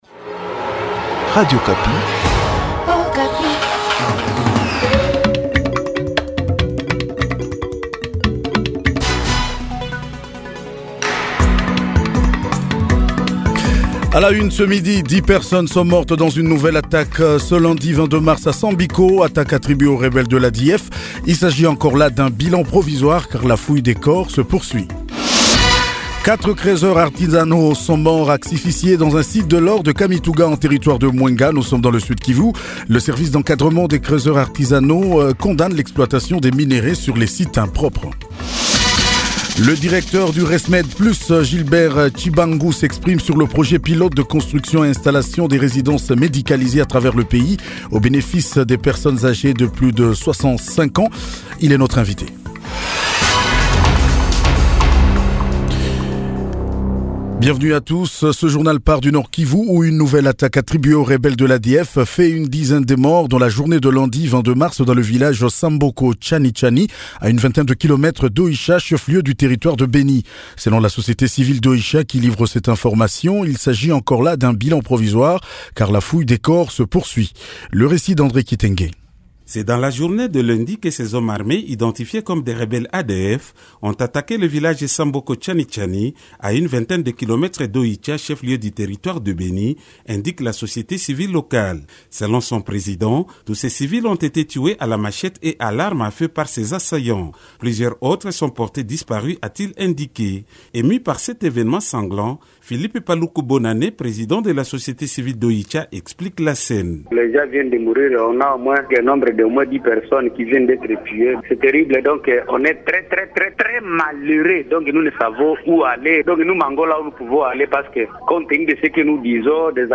JOURNAL DU MERCREDI 24 MARS 2021